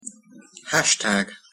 ˈhæʃˌtæɡ.